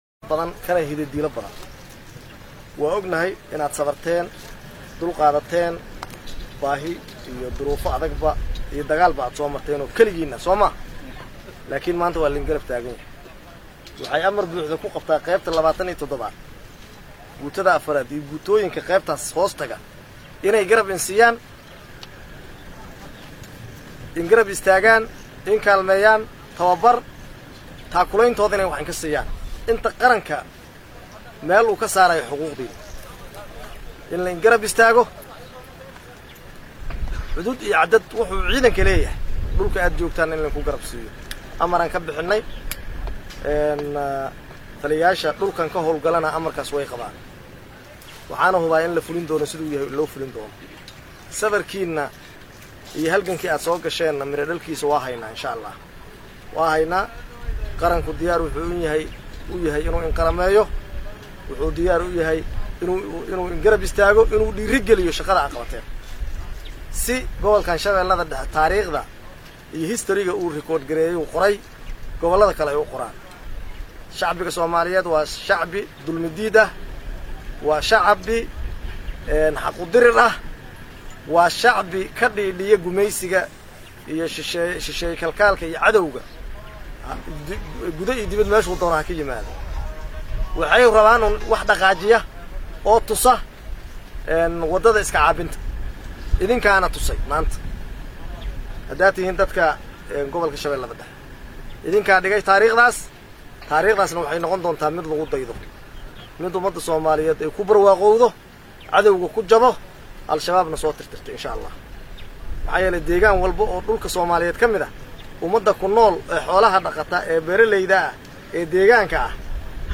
Taliyaha ayaa halkaas kula hadlay dhallinyarada macawiisleyda deegaanka ee isu taagay si ay ula dagaalamaan Al-Shabaab.
Halkaan hoose ka dhageyso hadalka taliye ku-xigeenka